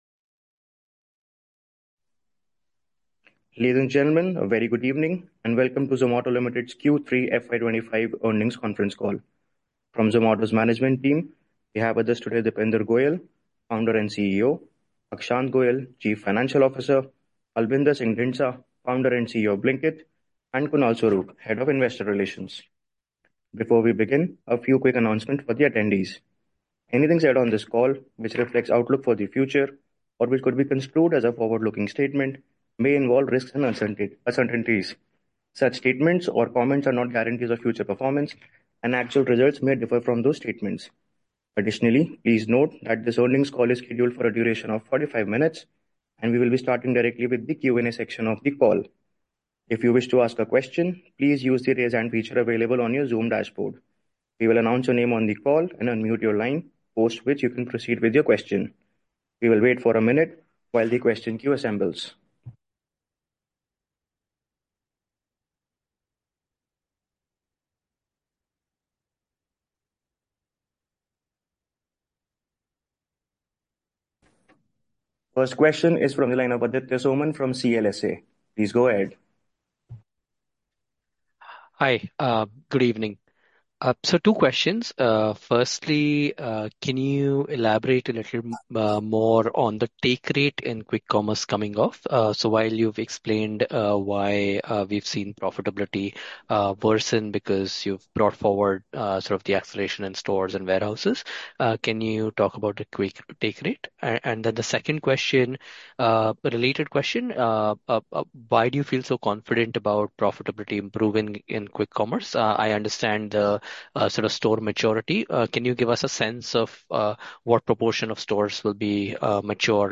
Q3FY25_earnings_call_replay.mp3